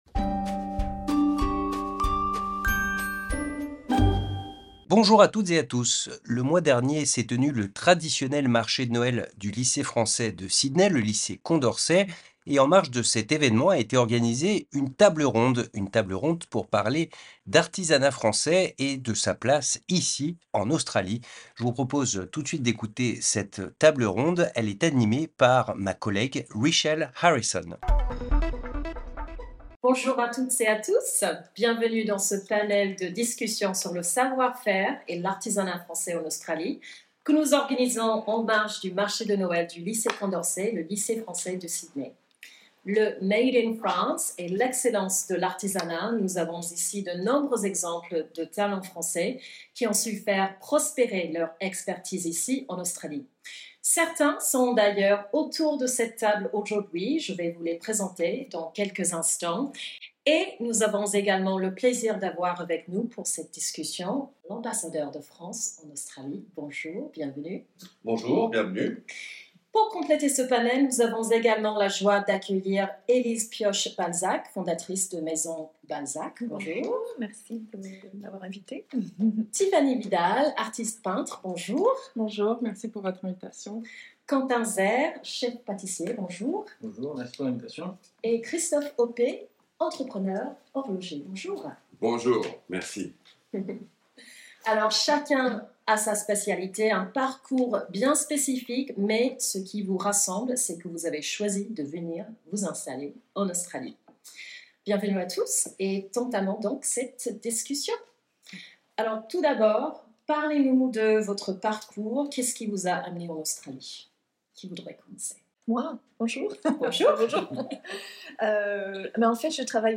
Table ronde sur l'artisanat français en Australie